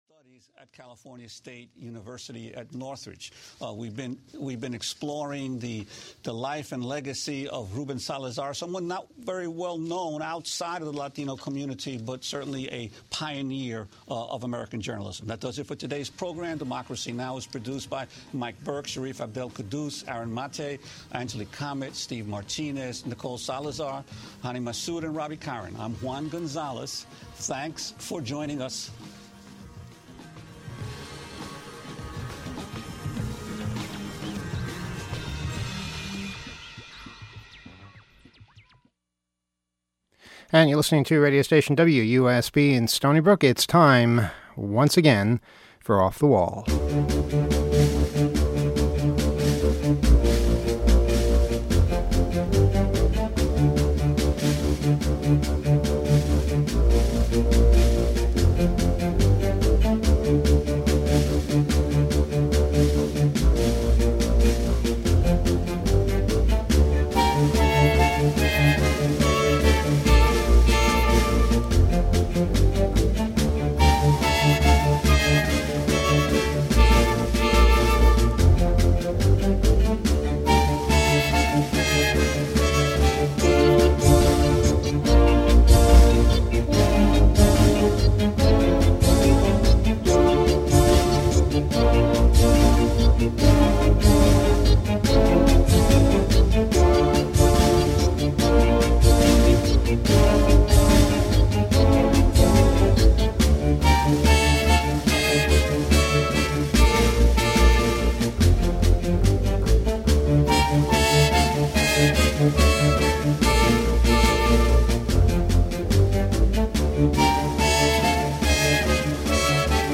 A live connection to the radio station at the ETH0 camp in Holland, tonight's show is the second part of the Bosnia special, waiting at the train station in Sarajevo, trying to track down a friend, Bosnian radio,